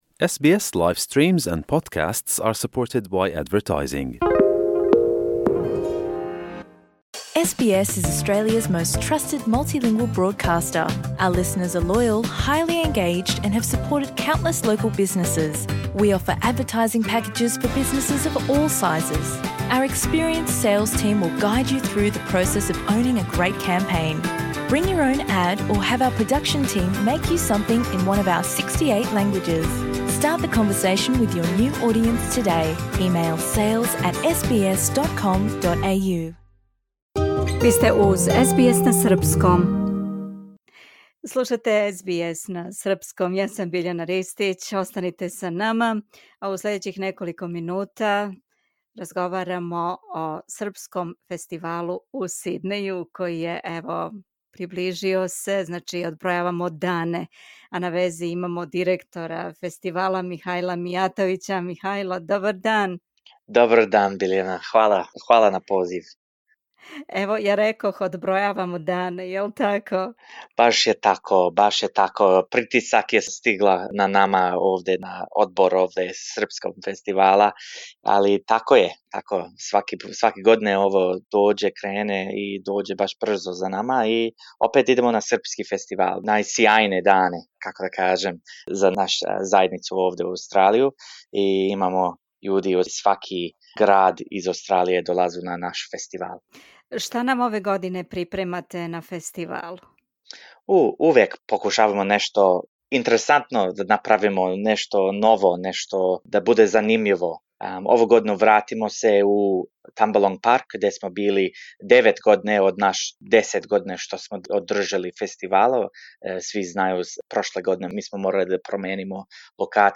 Ове године Српски фестивал у Сиднеју одржаће се једанаести пут и враћа се на стару локацију - у Тамбалонг парк у сиднејској луци. У разговору